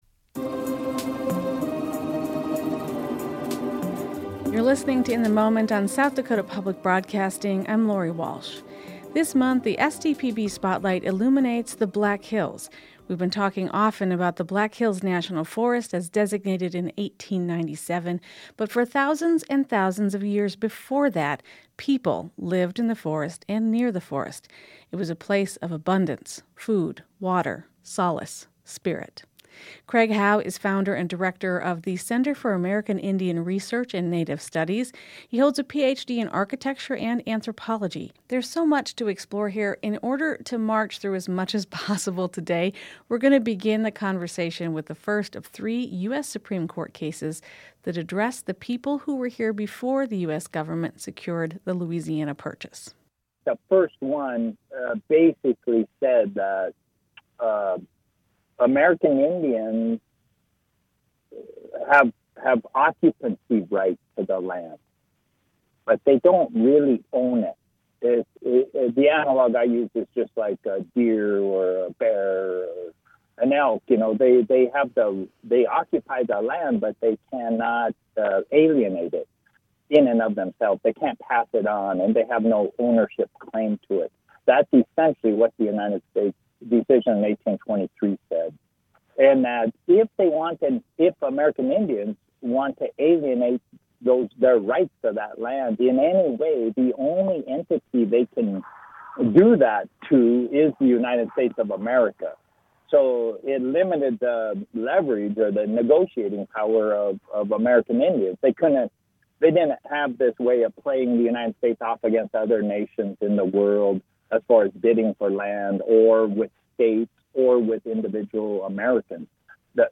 The Forest, the Treaty, and the Precedent for Returning Seized Land (16 min audio interview